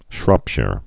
(shrŏpshîr, -shər, -shīr)